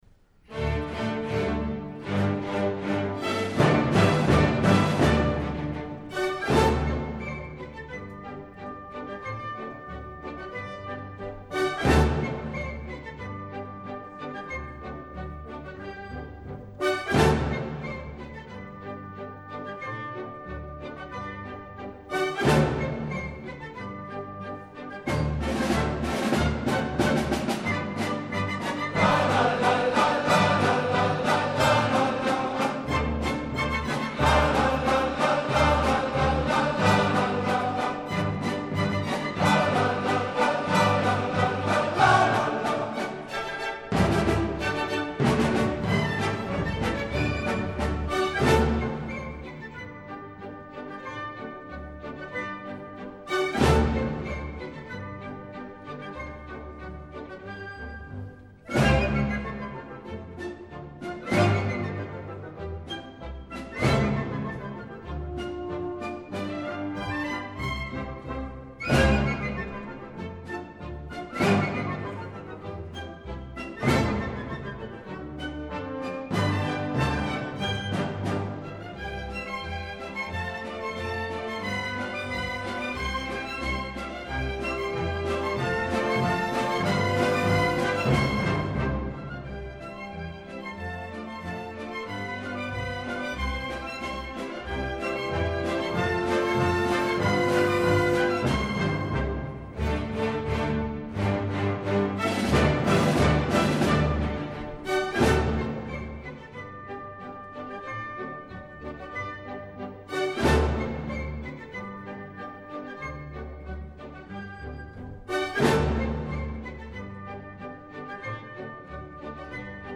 而今年乐团演奏家们则撩开了嗓门，“啦啦啦啦”之声如此直接而令人鼓舞。
不过唱片的录音效果似乎并不能算很出色。